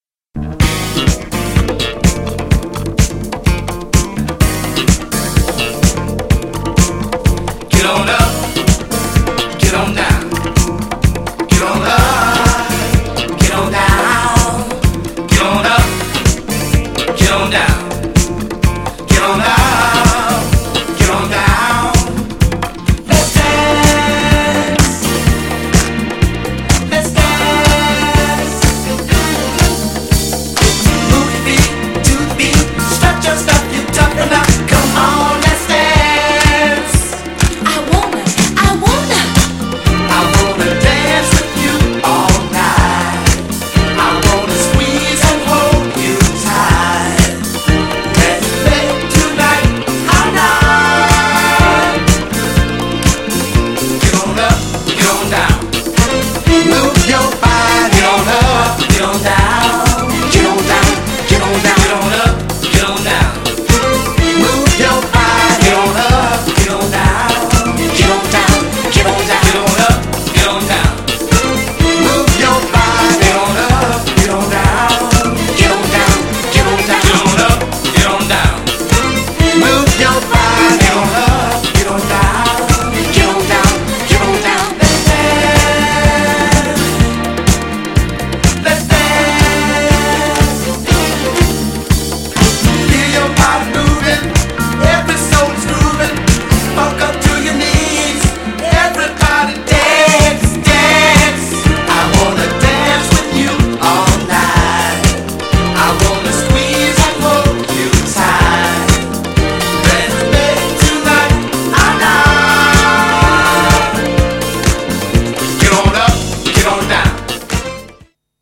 GENRE Dance Classic
BPM 71〜75BPM
# JAZZY
# クロスオーバー # スロー # フリーソウル # メロウ # レアグルーブ # 甘美 # 繊細